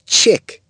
CHICK.mp3